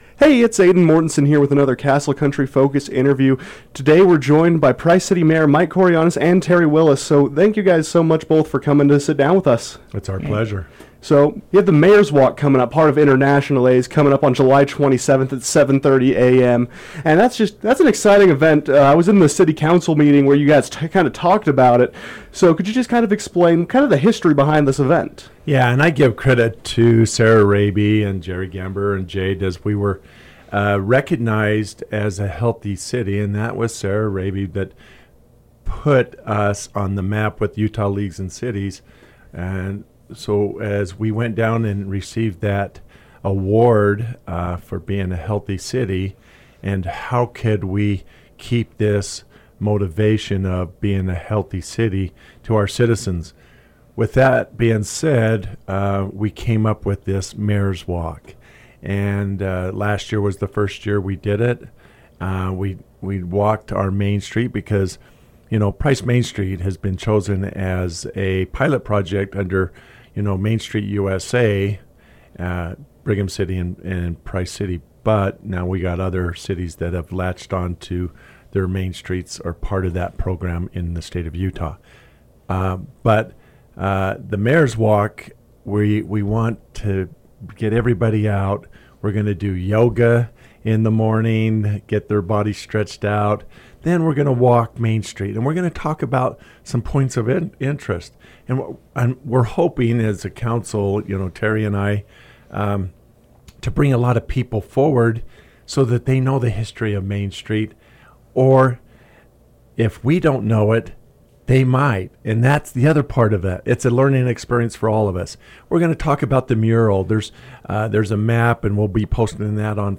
Price City Mayor Micheal Kourianos and City Council Member Terry Willis sat down with Castle Country Radio to talk about the upcoming event for the city.